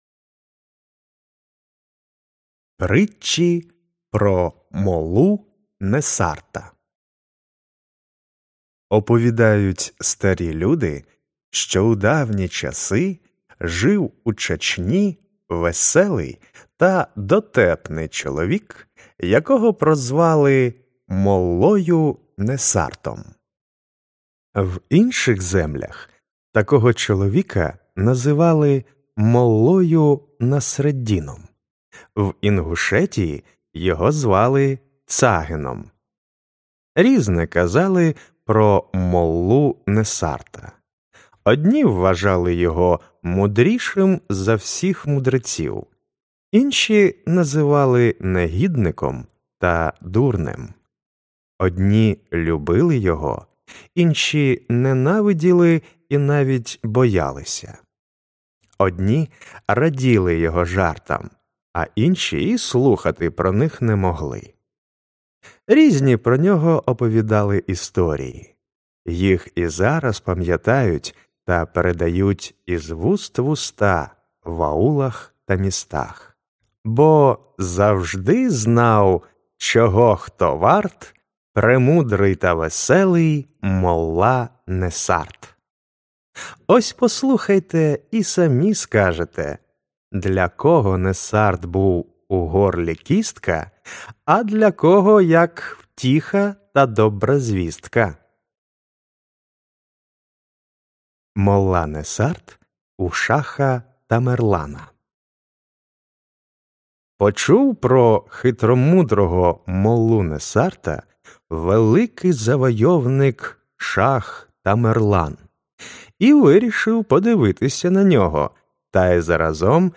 Аудіоказка Притчі про Моллу Несара